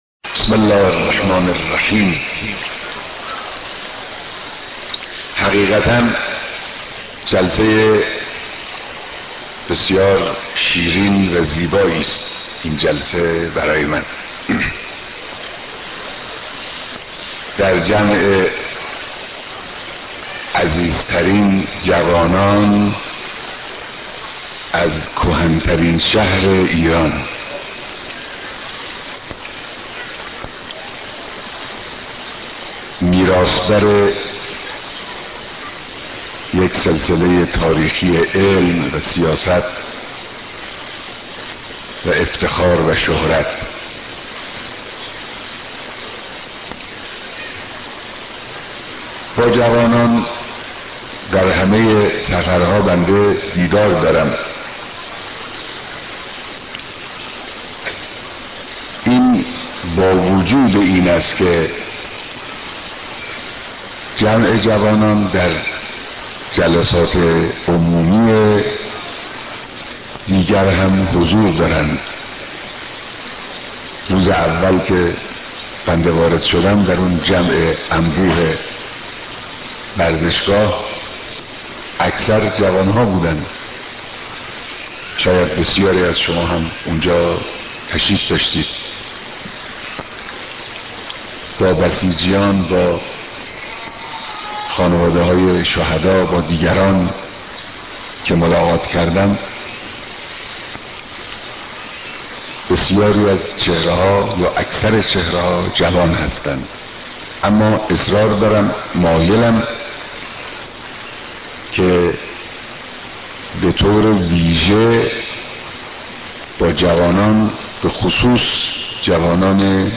بيانات رهبر انقلاب در جمع پرشور جوانان و دانشجويان استان همدان
بيانات در جمع پرشور جوانان و دانشجويان استان همدان